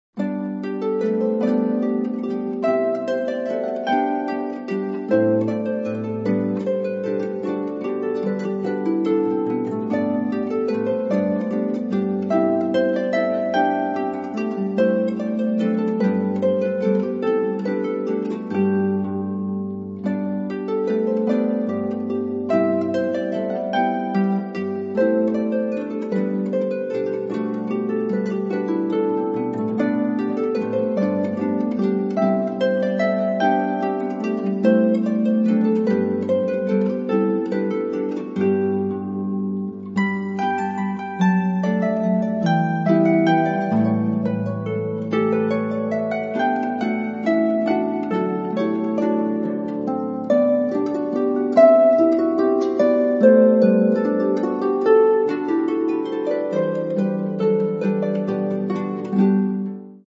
Genre: Classical